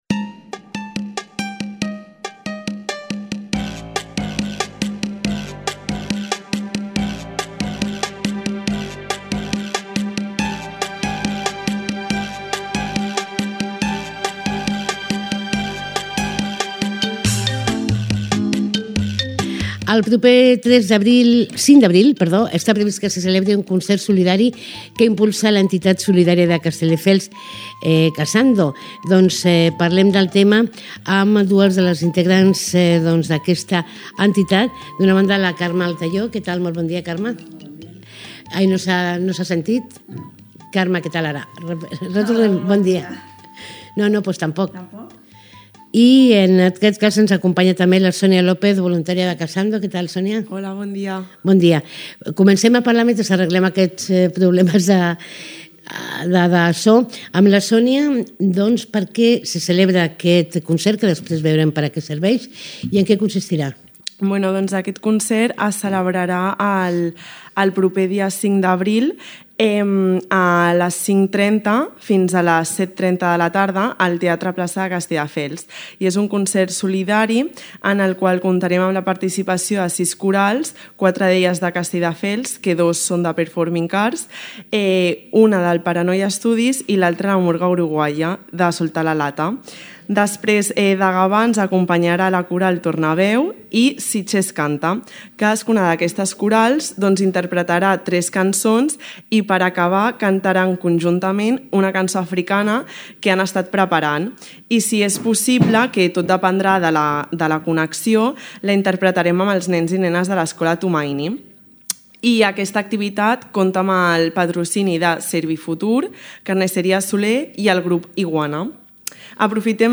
Escolta l’entrevista a Radio Castelldefels